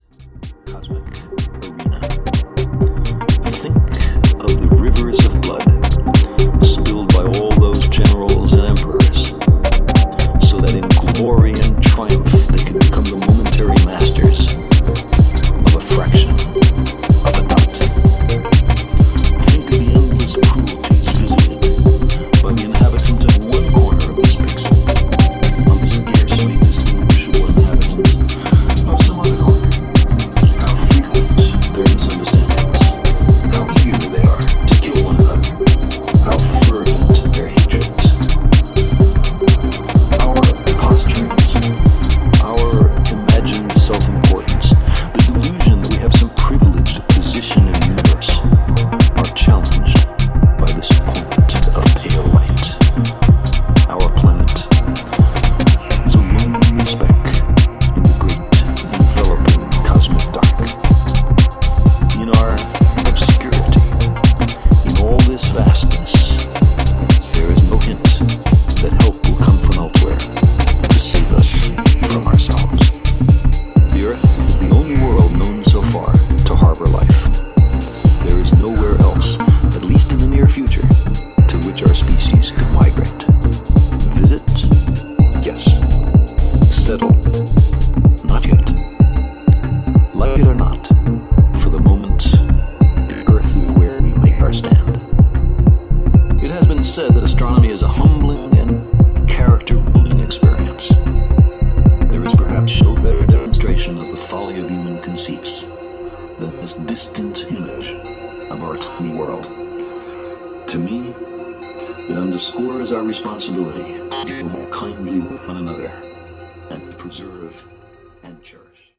channels deep, chugging house,